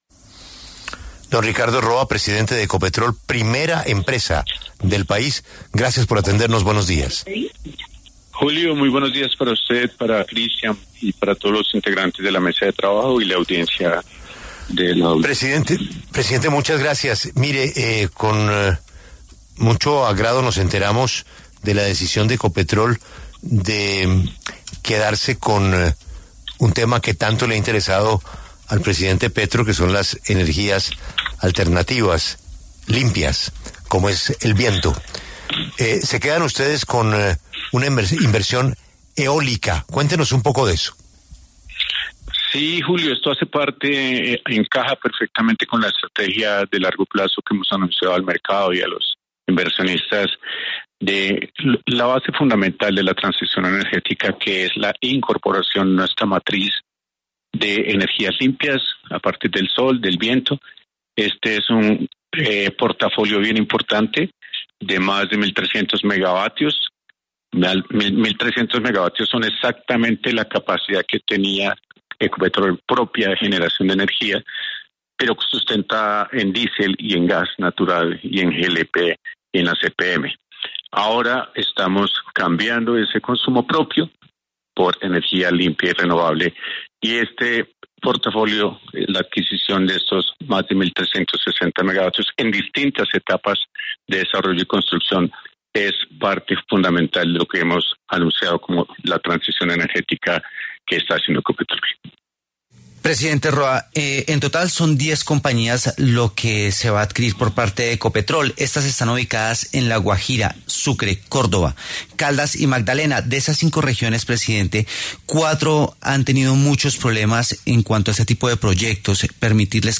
Ricardo Roa, presidente de Ecopetrol, habló en La W y dio un parte de tranquilidad y garantía sobre las licencias y permisos ambientales que se necesitan para que la nueva inversión en eólicos y solares sea exitosa.